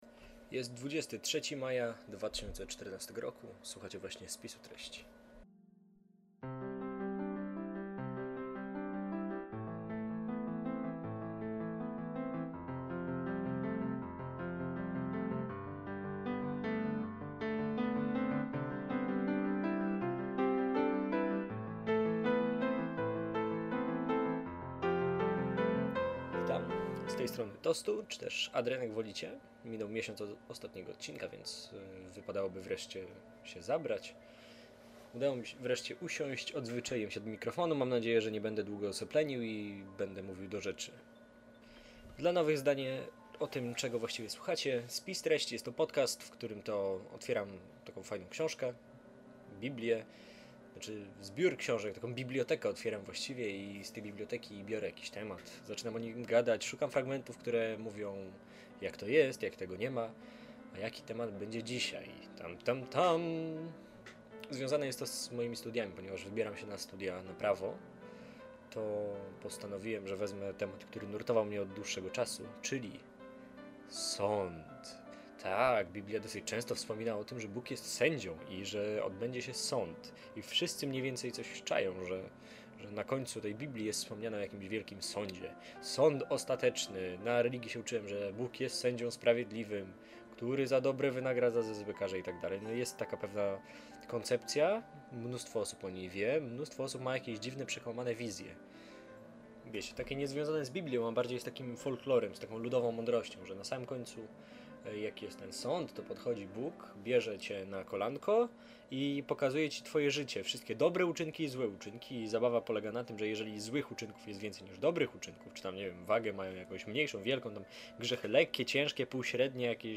Podcast o Biblii i wszystkim, co z nią związane. Swobodne gadanie o Bogu prostym, nieteologicznym językiem.
Odcinek jak zwykle ciekawy, tylko strasznie cichy......